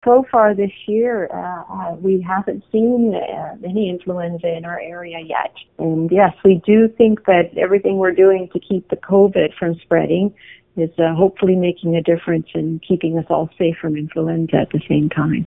Medical Officer of Health, Dr. Joyce Lock had this to say about the flu in our area.